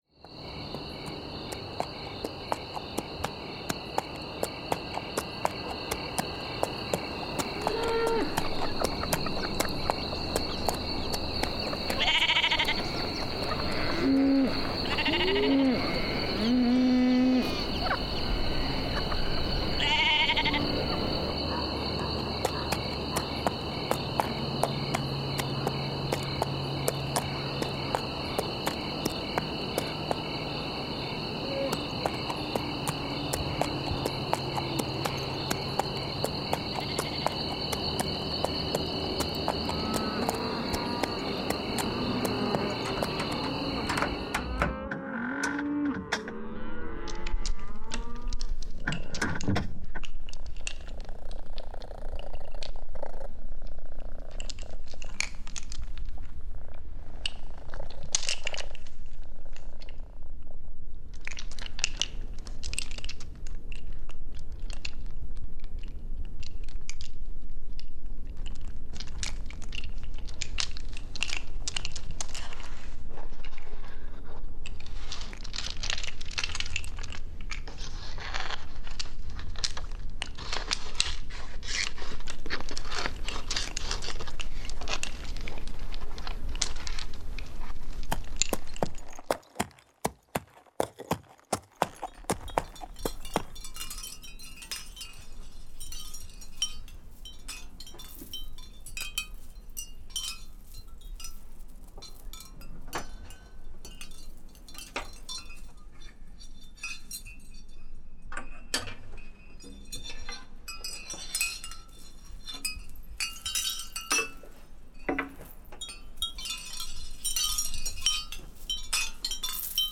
Paisagem Sonora de Emma | MATLIT: Materialities of Literature